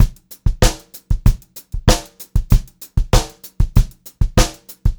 96POPBEAT1-R.wav